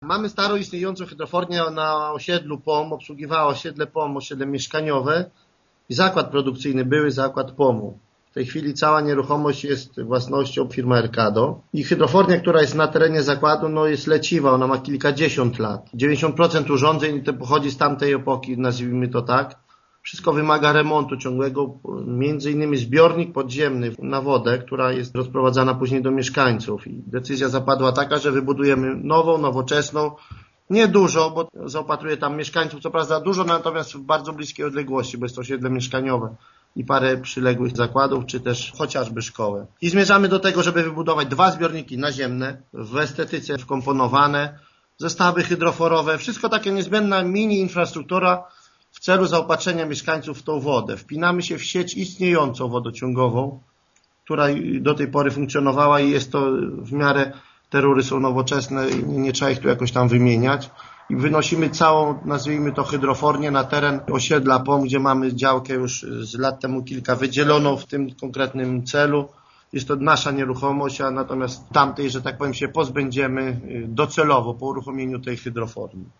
Zastępca wójta Tomasz Najs tłumaczy, że realizacja tej inwestycji jest niezbędna: